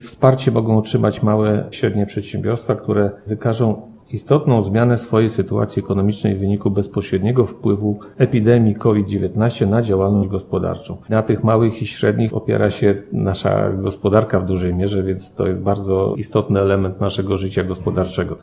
Zaznacza zastępca prezydenta Ełku Mirosław Hołubowicz.